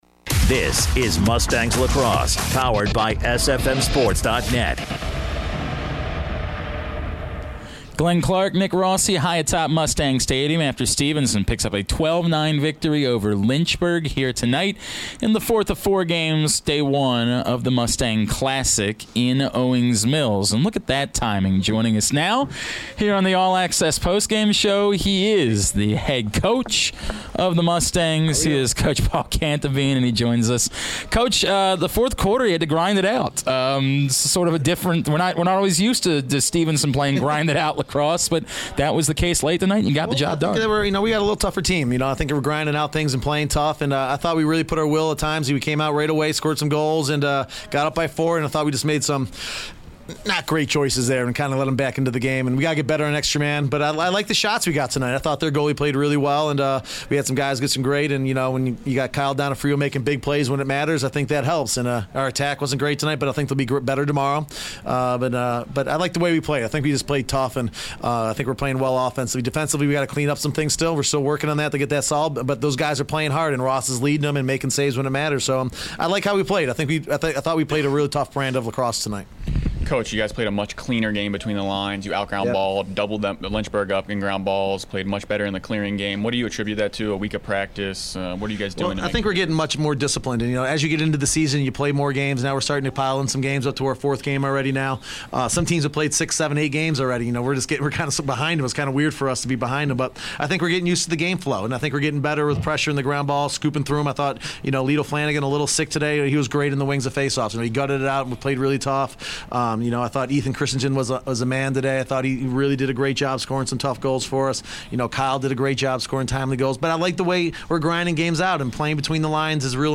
3/17/17: Stevenson Lacrosse Post Game Interview